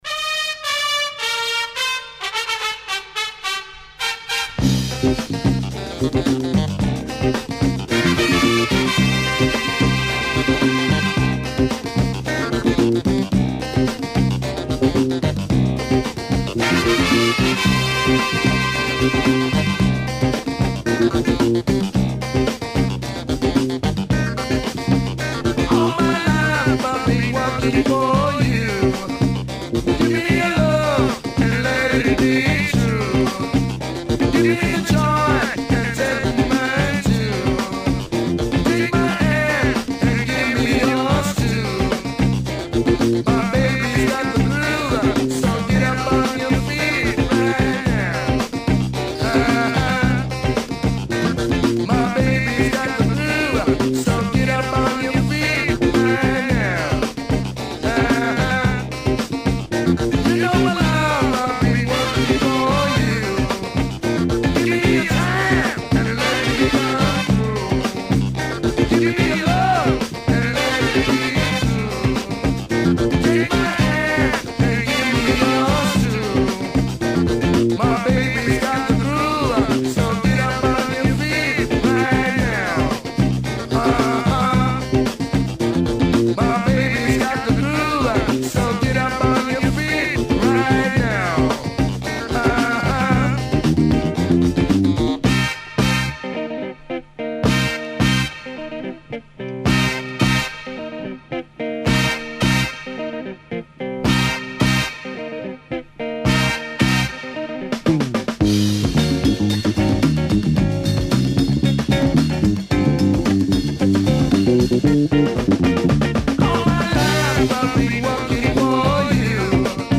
afro funk tune